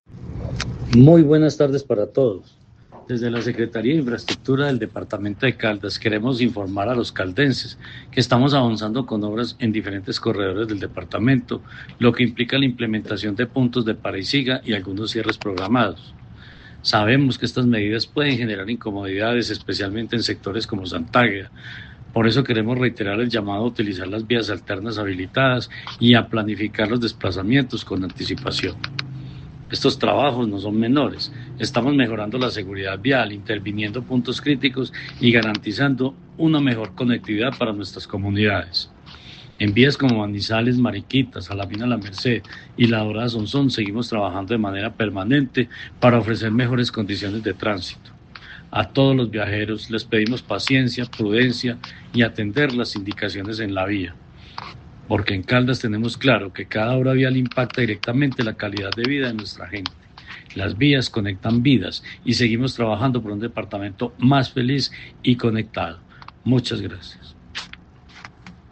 art16320-secretario-de-infraestrutura-jorge-ricardo-gutierrez-cardona-.mp3